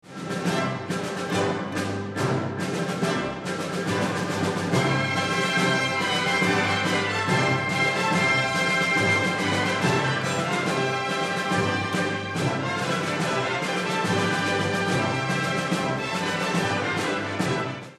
Considéré d'abord par Ravel comme un simple exercice, le Boléro est composé d'un seul thème, répété du début à la fin, sans autre modification qu'une variation de timbres, un crescendo et, in extremis, une modulation (changement de tonalité).